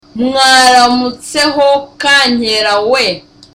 Dialogue
(Smiling)